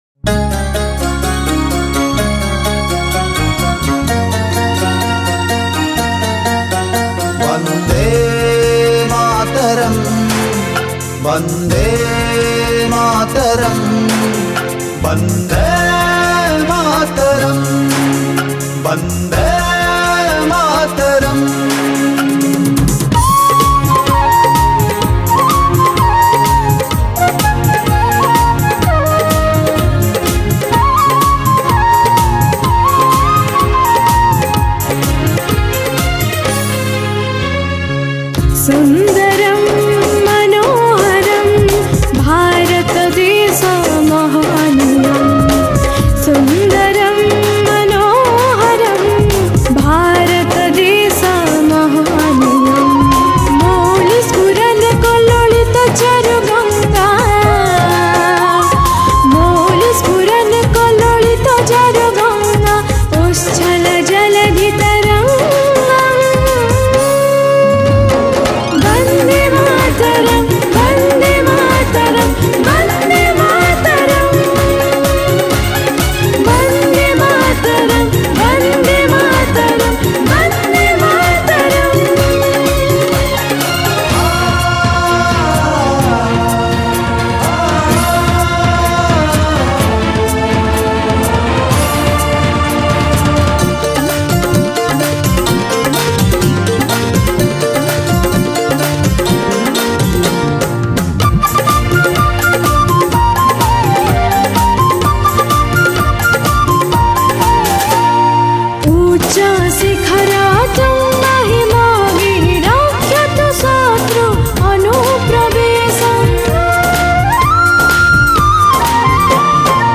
Independents Day & Republic Day Special Song